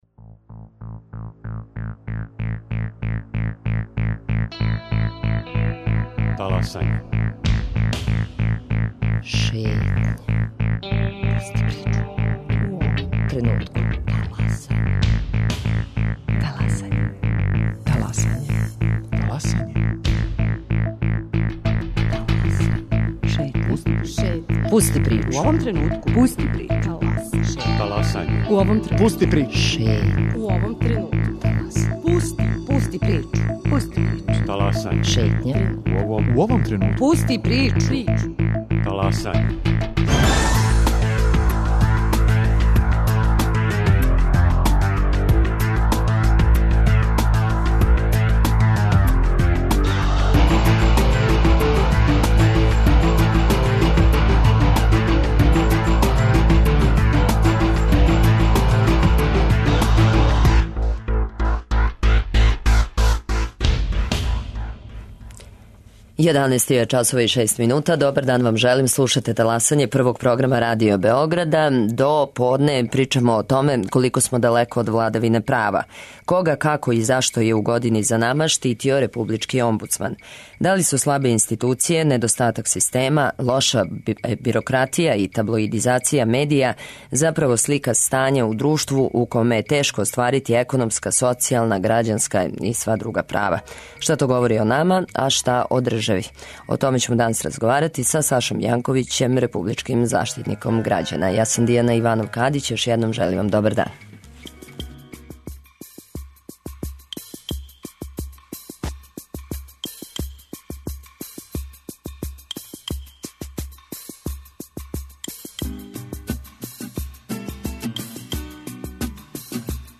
Гост: Саша Јанковић, заштитник грађана